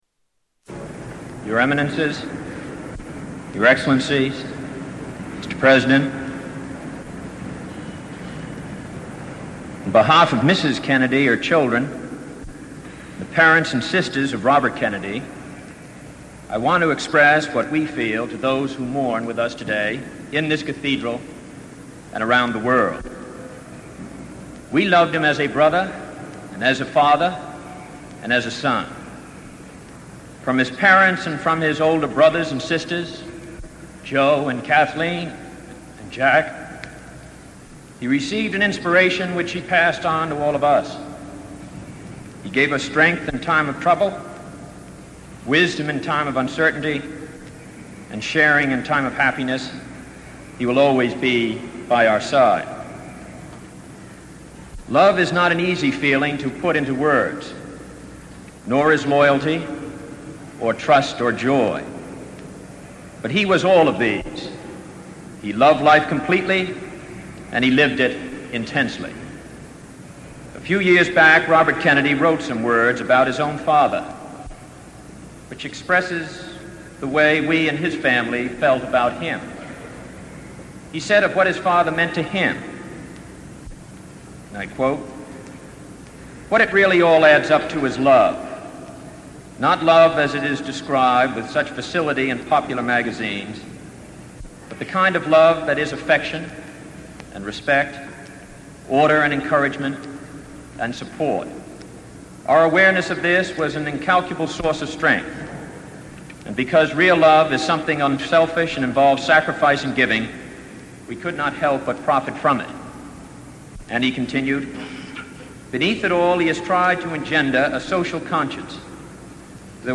Ted Kennedy Eulogy for Robert Francis